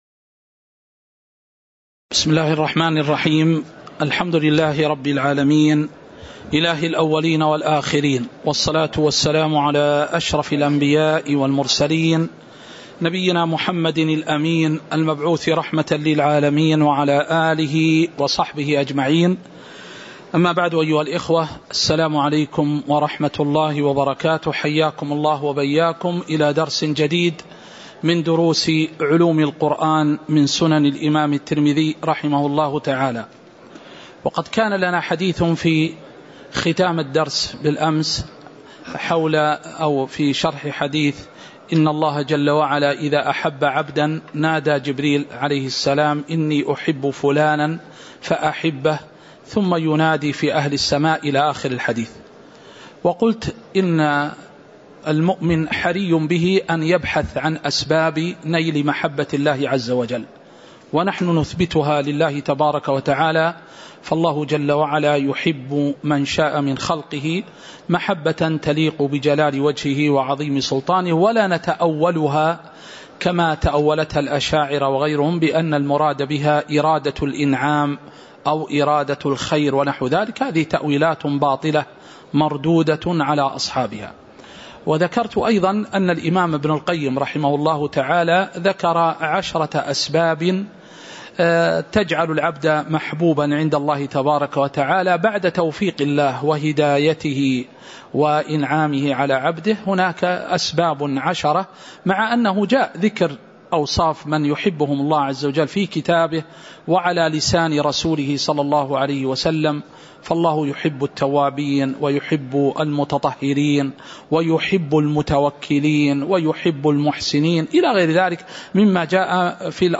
تاريخ النشر ٣٠ جمادى الأولى ١٤٤٣ هـ المكان: المسجد النبوي الشيخ